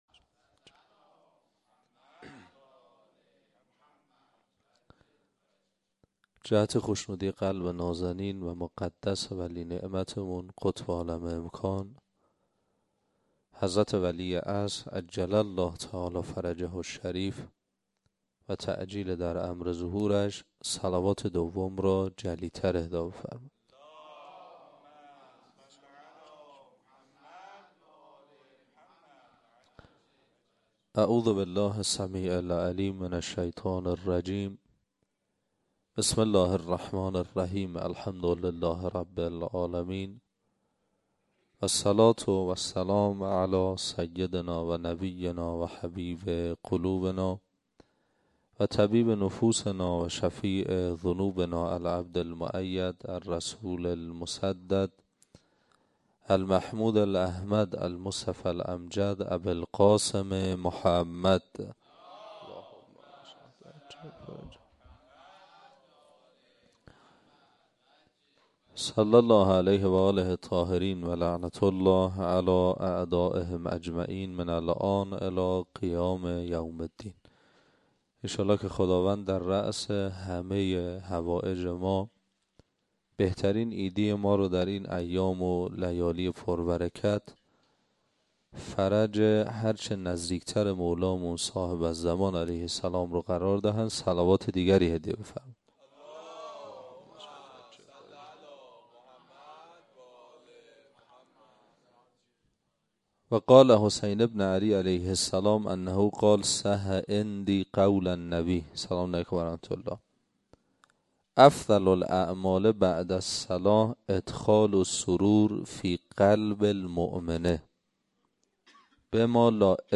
00سخنرانی